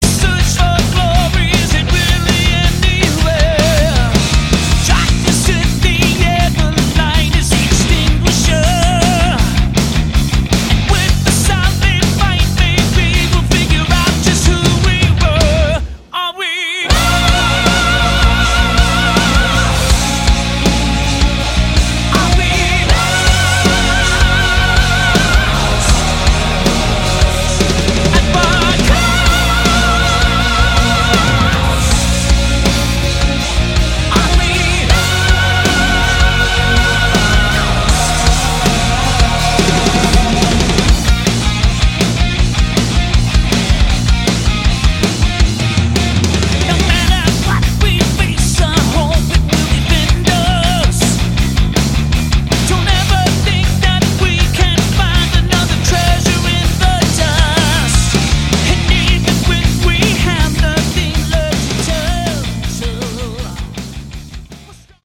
Category: Hard Rock
lead vocals, guitar
drums, background vocals